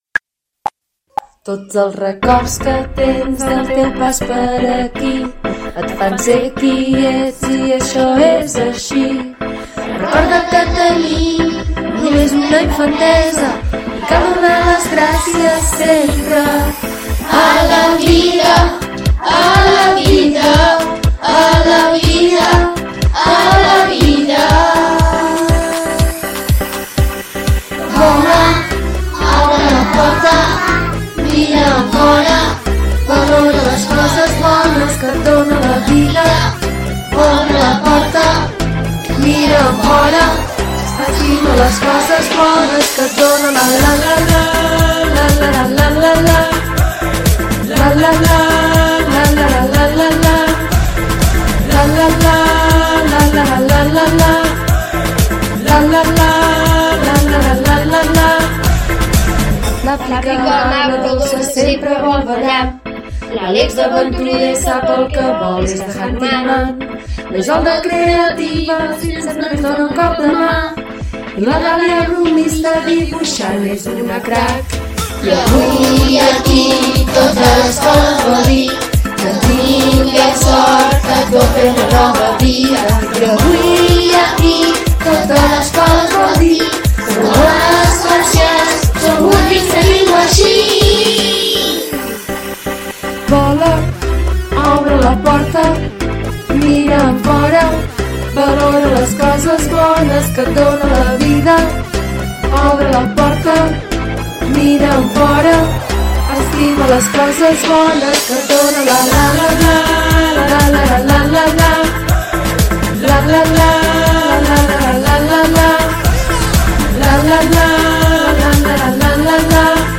L’ÚLTIM DIA vam passar-lo d’una manera ben ESPECIAL: a primera hora vam acomiadar els NENS i NENES de 6è amb diferents actuacions i detalls per desitjar-los MOLTA SORT a l’institut.
Aquesta és la cançó que els vam dedicar: